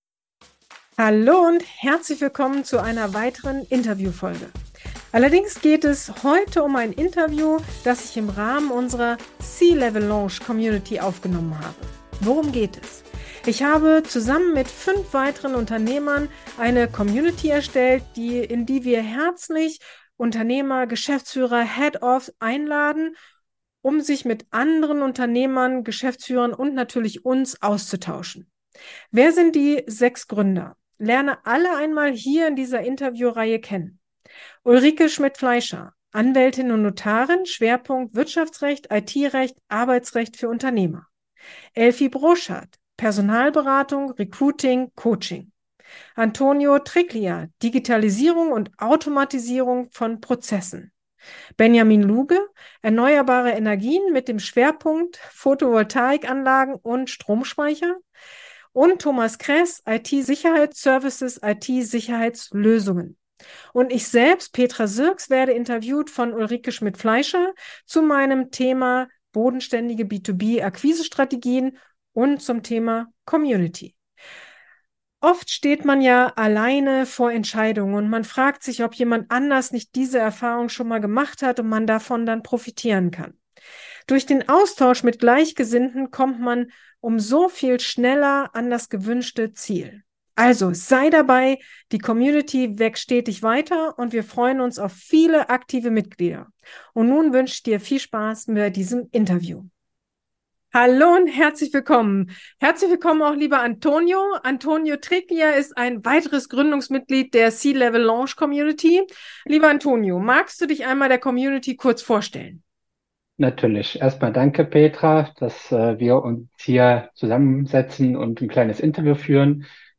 Unternehmer-Community: C-Level Lounge | Interview